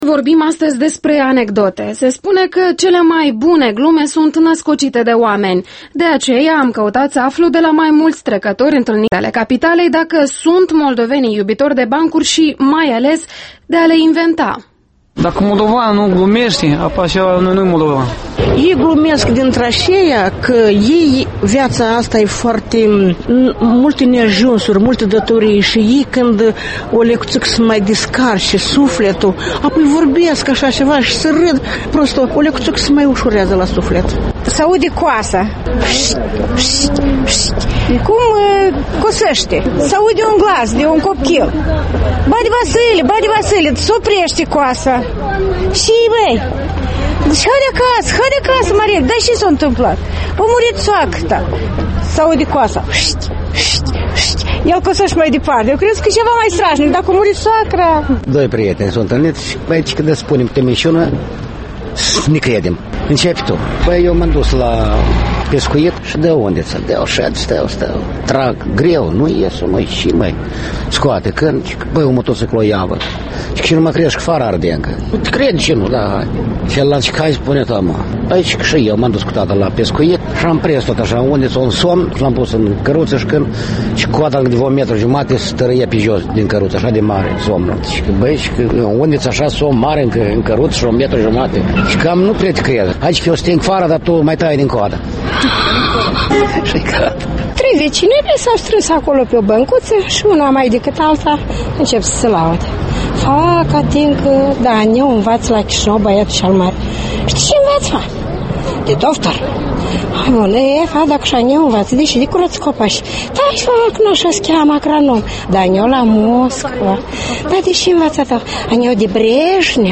Interviul matinal EL: Anatol Durbală despre virtuțile capacității de a rîde